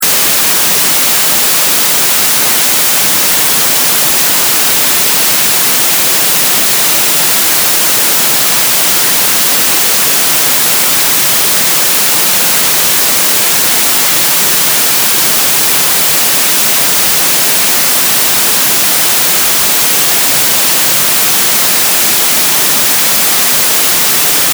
si tu entends un genre de vent de mer souffler, c'est qu'il n'y a pas de filtre intégré...
si tu entends juste un petit "fffuuhh" lointain et sourd-étouffé c'est qu'il y a un filtre passif... :°;)